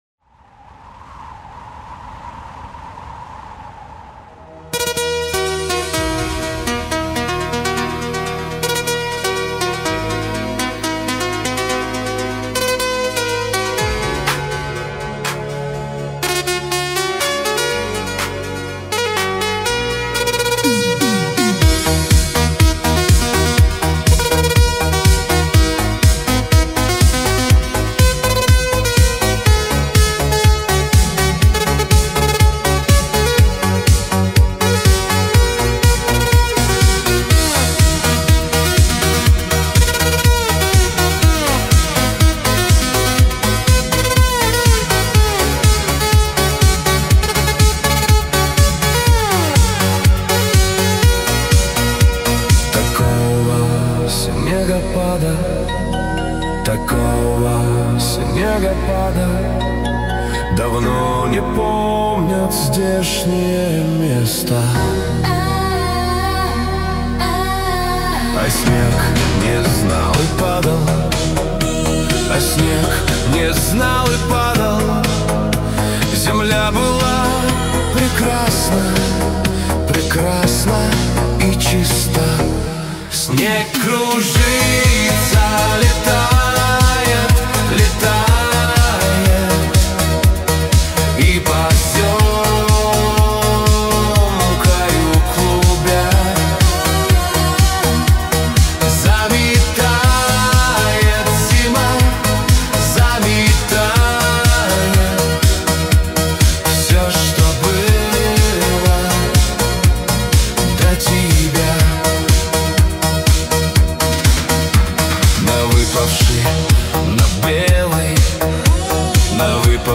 Танцевальный Шансон